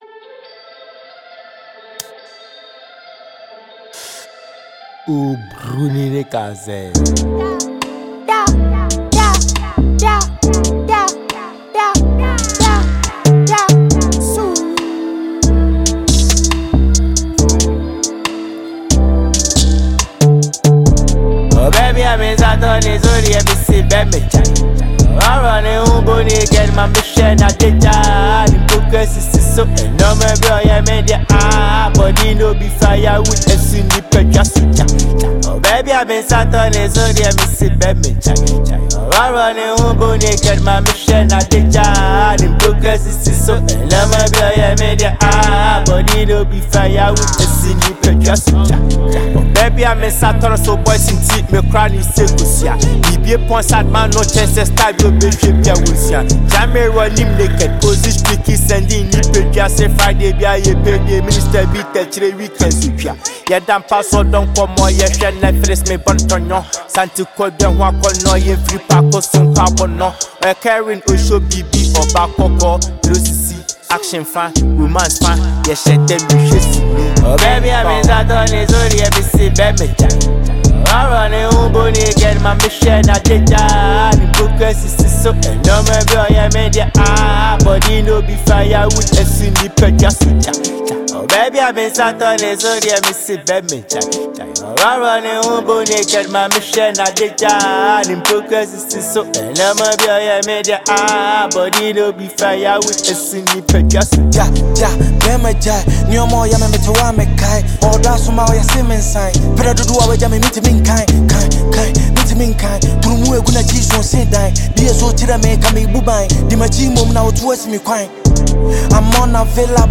a jam tune